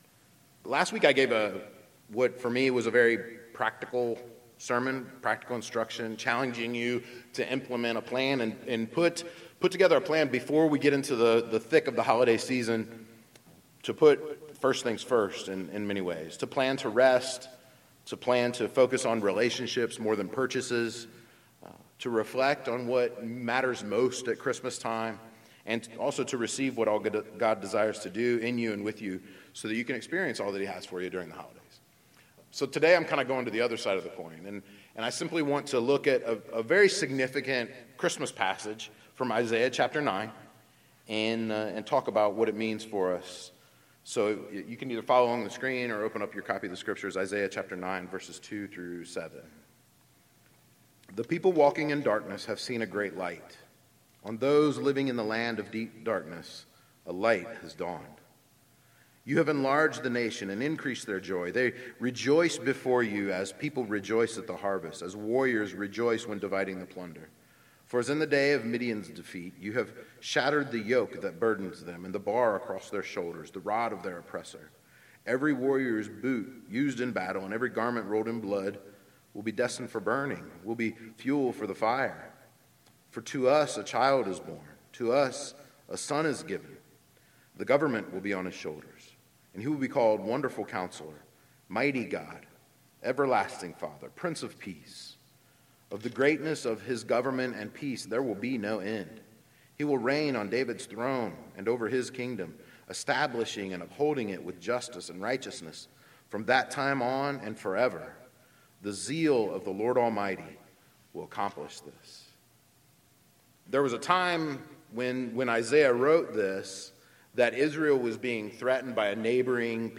Christmastime at SCC 2019 – Sermon 2